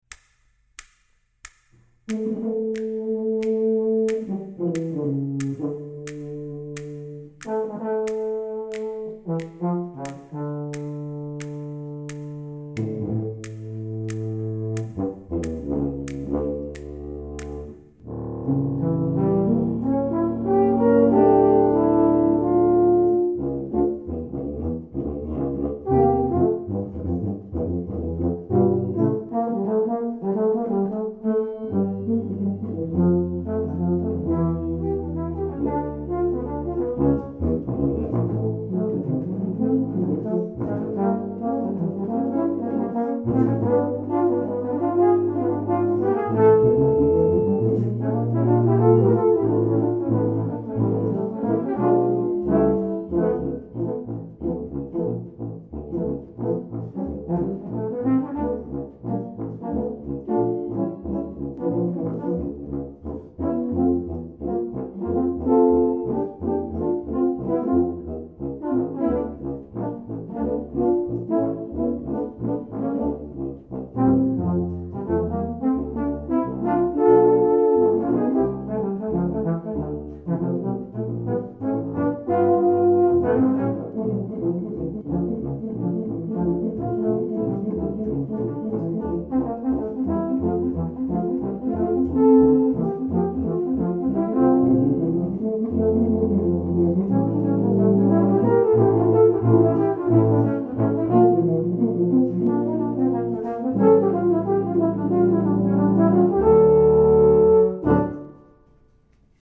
For Tuba Quartet (EETT)
Jazzy version.